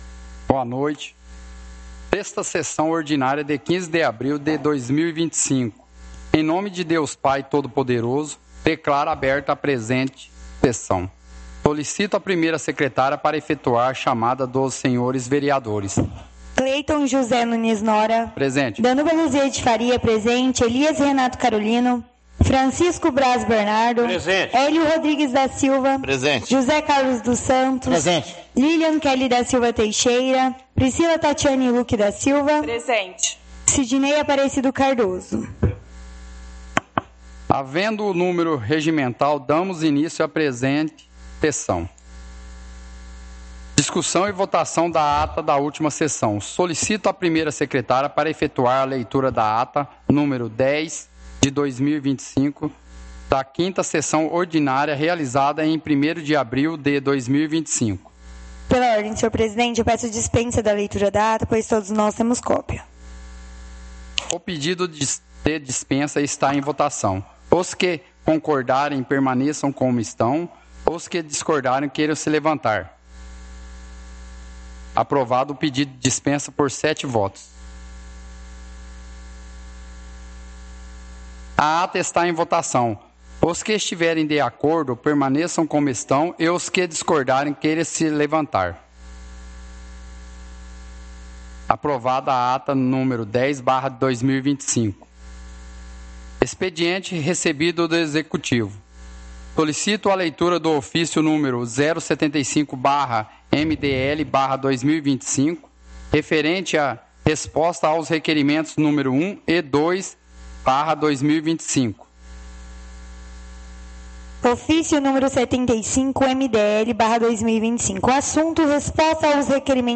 Áudio da 6ª Sessão Ordinária – 15/04/2025